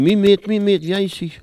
Mémoires et Patrimoines vivants - RaddO est une base de données d'archives iconographiques et sonores.
Cri pour appeler le chat ( prononcer le cri )